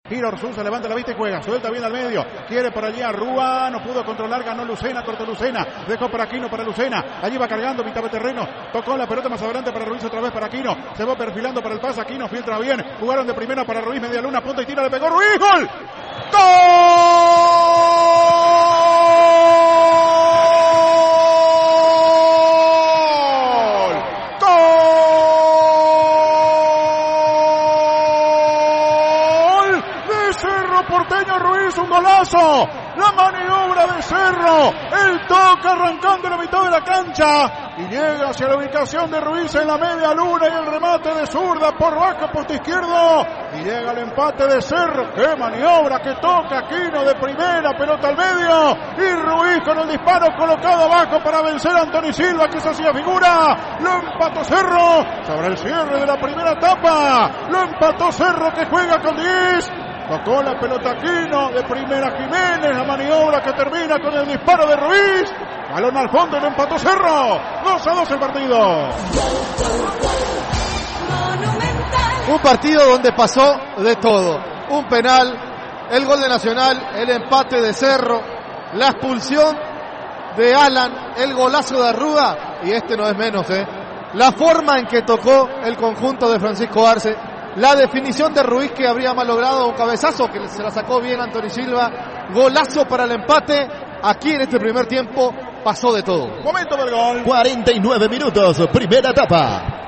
Relatos y comentarios del equipo de Fútbol a lo Grande.